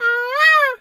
bird_peacock_squawk_13.wav